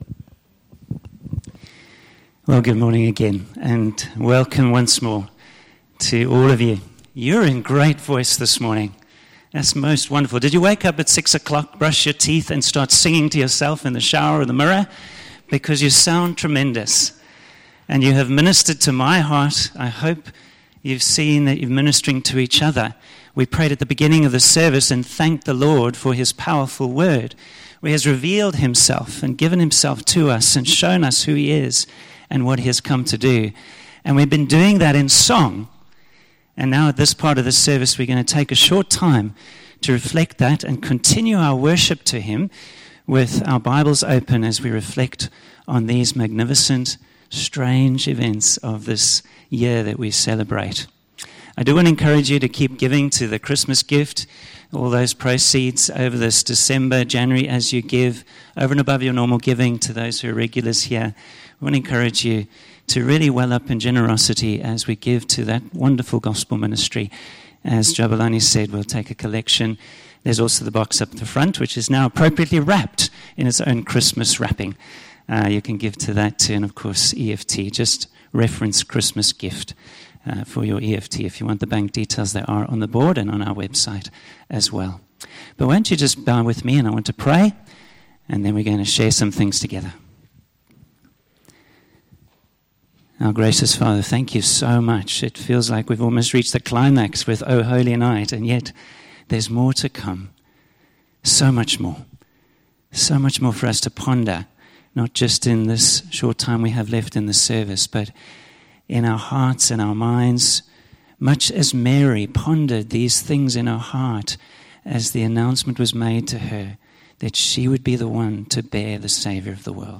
Christmas Carols Service